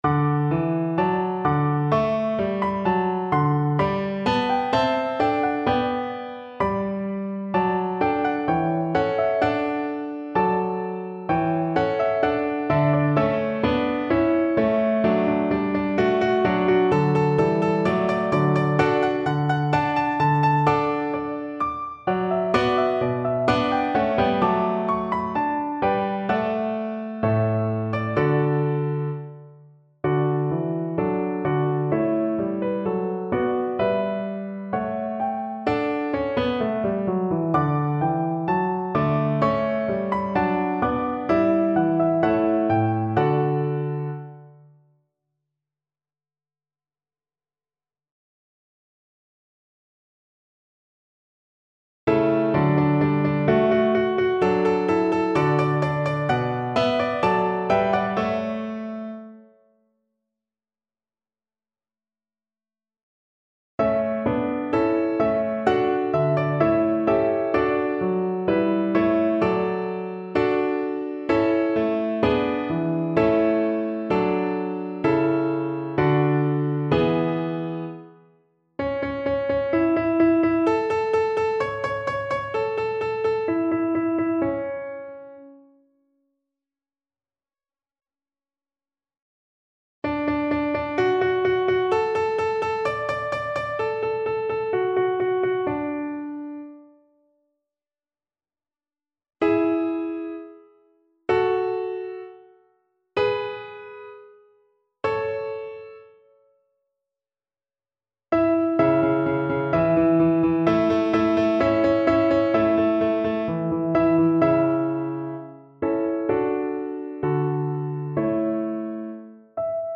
Violin
4/4 (View more 4/4 Music)
D major (Sounding Pitch) (View more D major Music for Violin )
Moderato
Classical (View more Classical Violin Music)